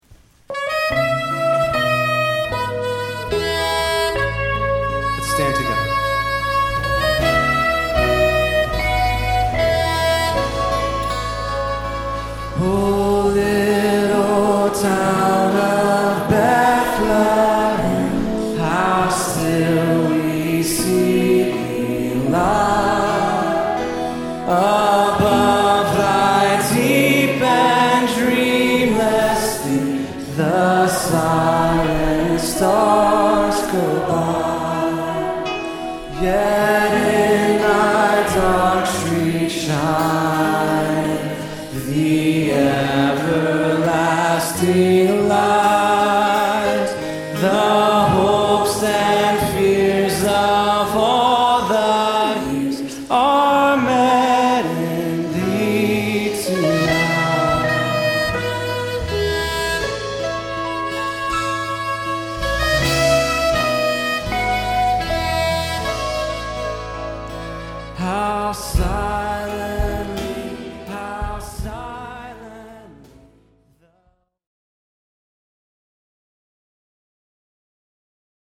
We sang verses 1, 3, and 5 of this hymn and you can hear a bit of the feel we gave it below.